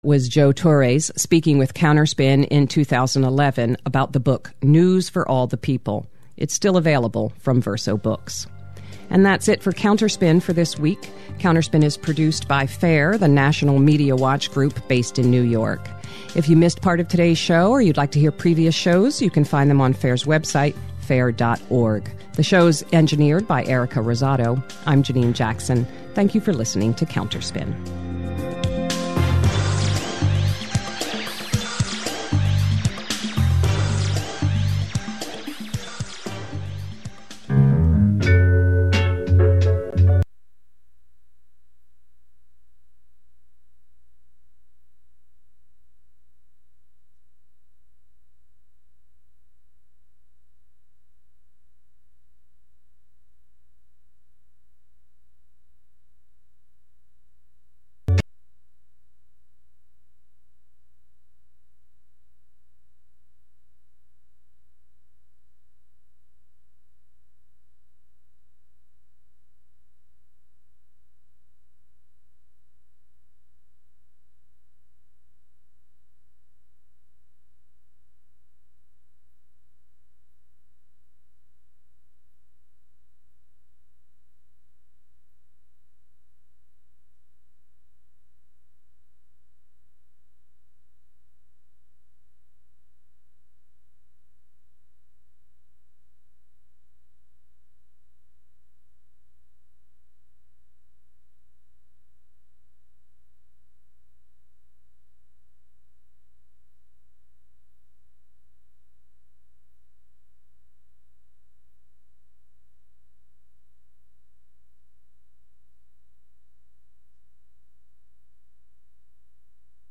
Every 1st Monday from 7:00 pm to 8:00 pm Let’s Talk About Race (LTAR), a new intergenerational, roundtable discussion of independent national journalists featuring rigorous conversations and analysis of news coverage and the role race plays in politics, government, economy, education, and health.